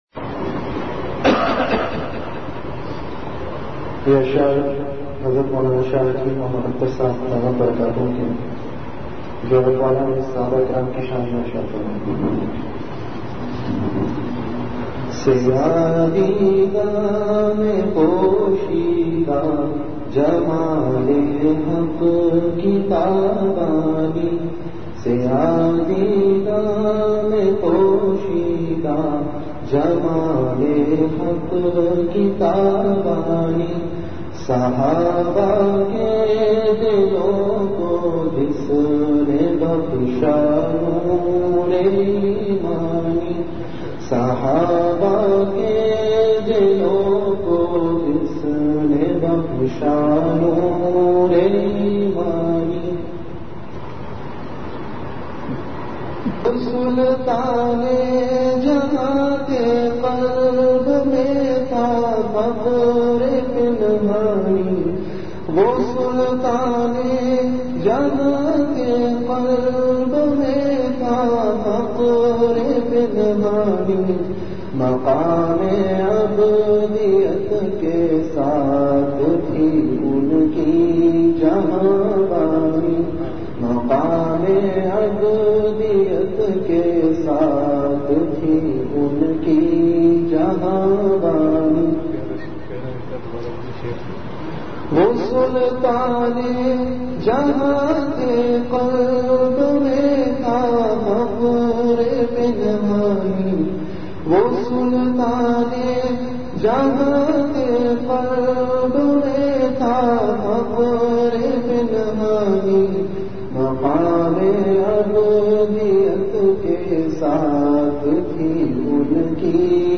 Majlis-e-Zikr
Venue Home Event / Time After Isha Prayer